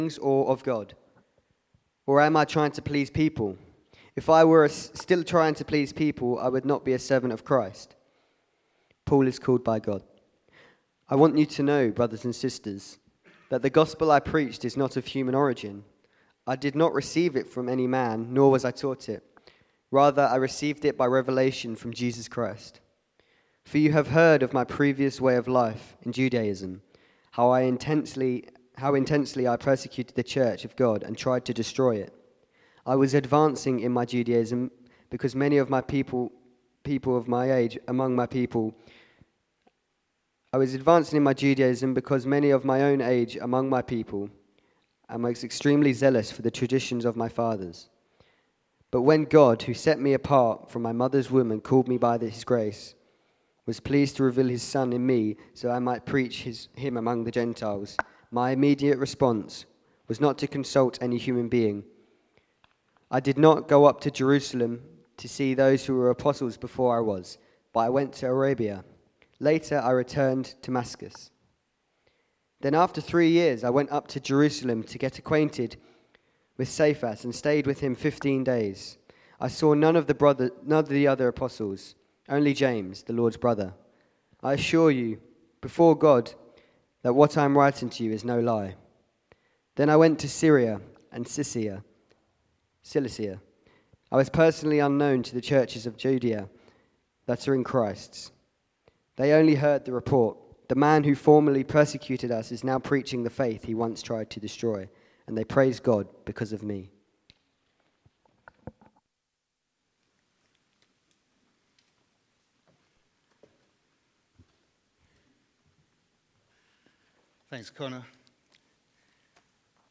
A message from the series "No Longer Slaves."